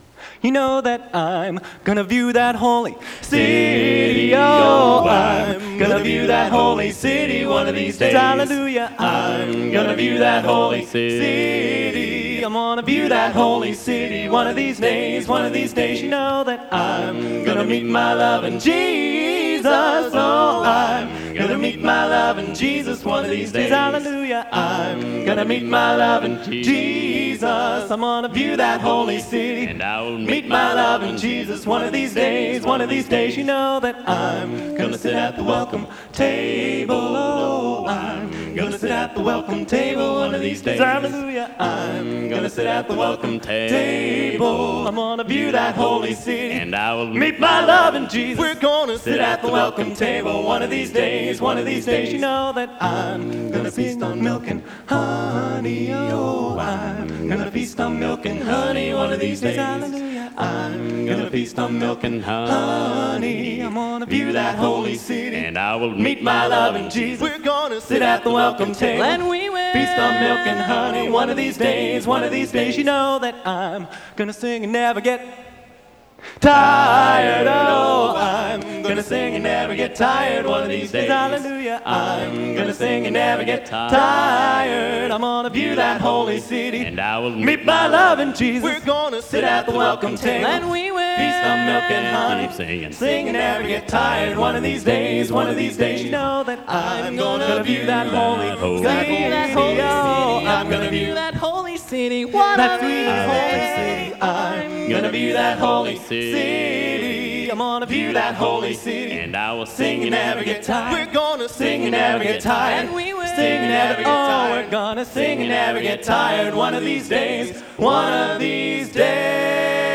Genre: A Cappella Gospel | Type: Specialty